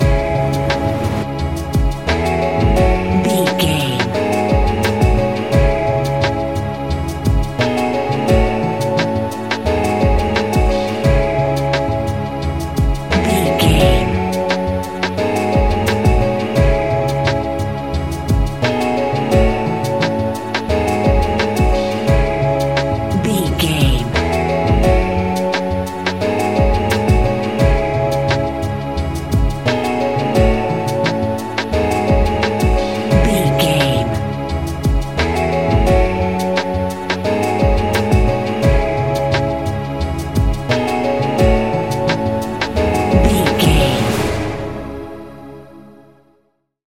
Ionian/Major
C♯
laid back
Lounge
sparse
new age
chilled electronica
ambient
atmospheric
morphing